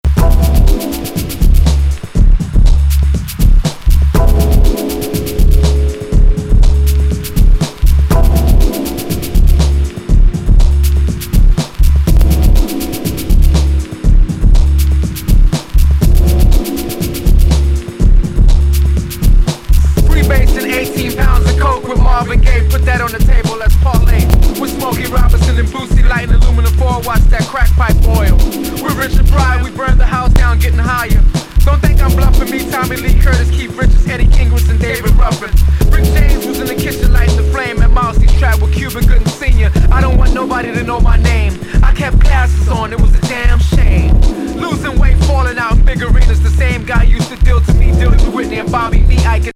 ドイツ・アンダーグラウンドの新世代、音響的なサウンドは一貫しつつも、